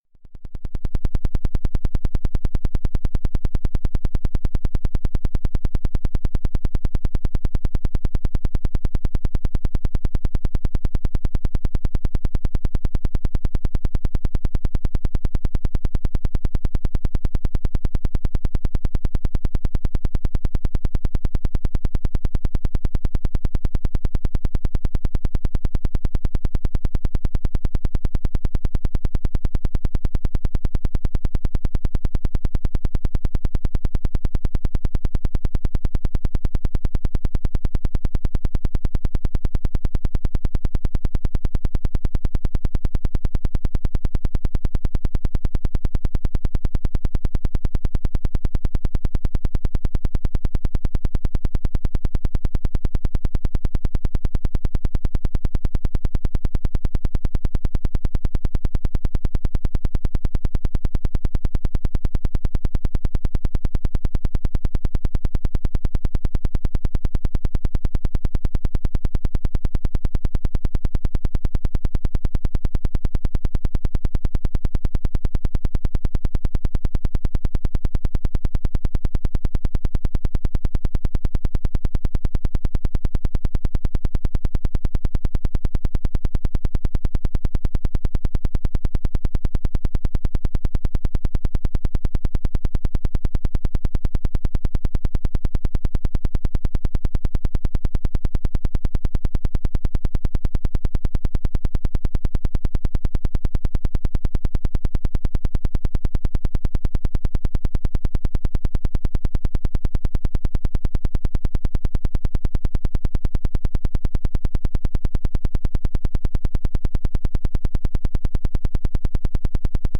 17. PEMF 10 Hz - Cell Growth and Regeneration Sawtooth.mp3